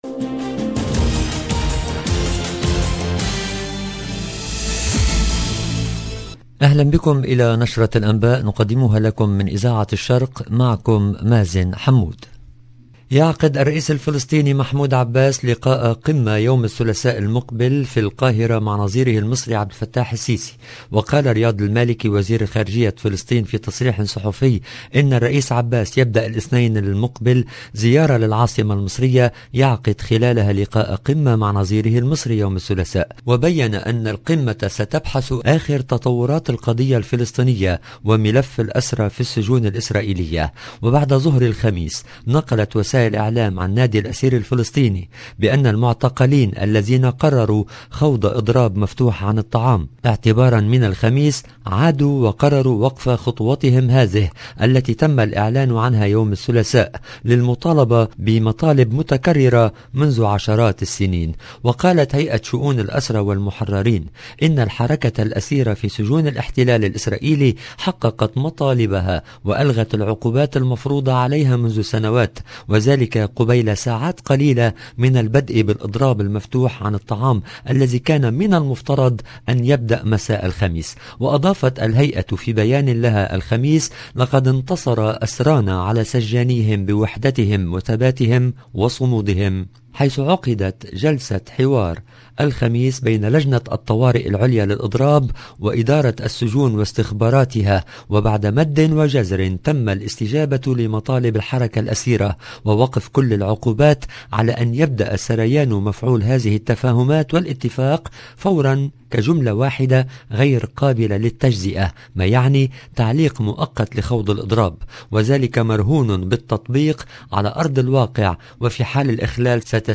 LE JOURNAL EN LANGUE ARABE DU SOIR DU 1/09/22